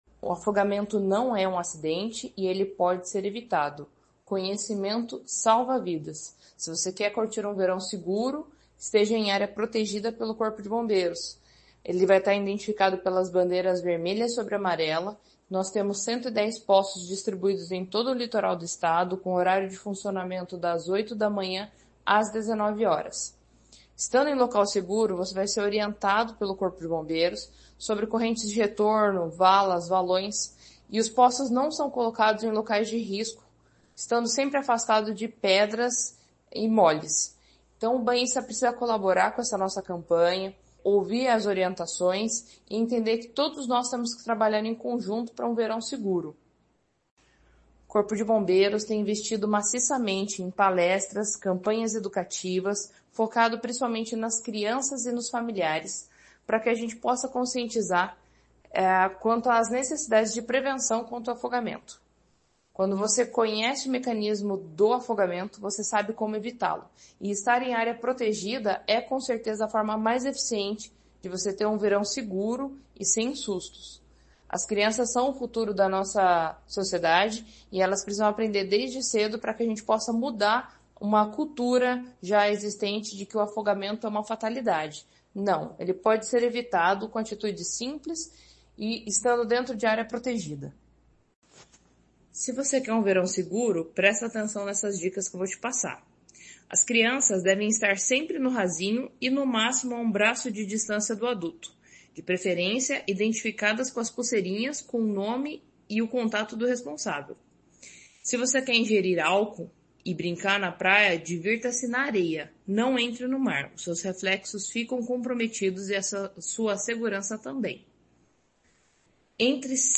Sonora da capitã do Corpo de Bombeiros Militar do Paraná